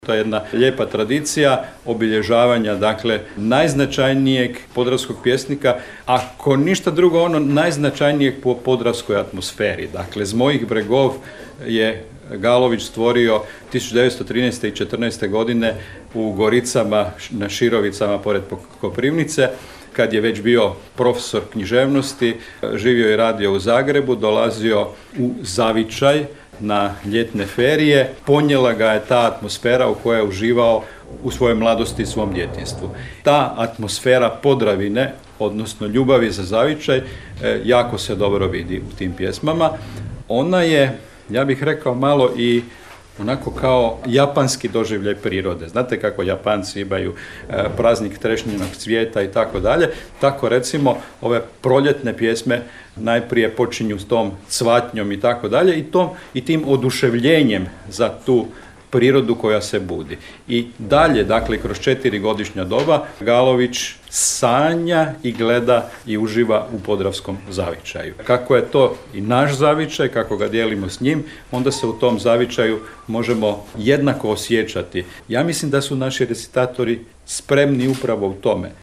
Gradska knjižnica Đurđevac; Održano 17. natjecanje u recitiranju pjesama Frana Galovića